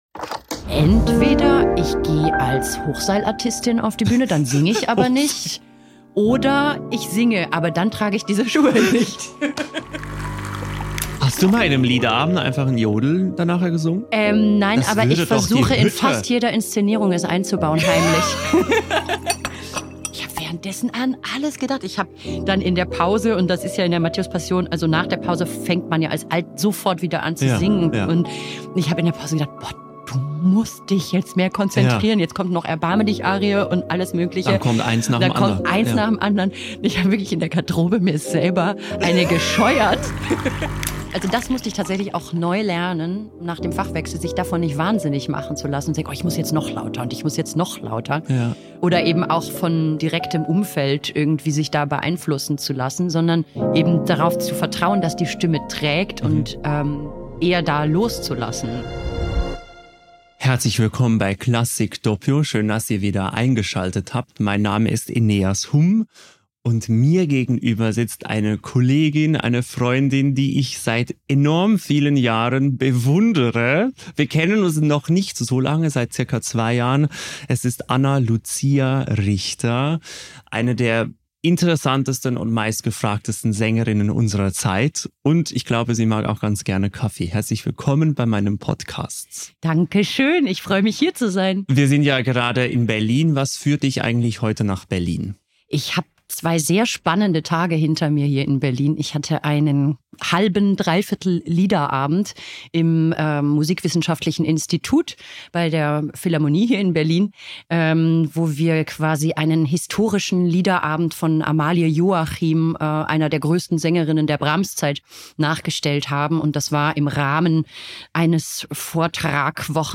Im Gespräch teilt sie Geschichten aus dem Sängerleben, Gedanken über Wandel und Neuanfang – begleitet, wie immer bei Klassik Doppio, von gutem Kaffee.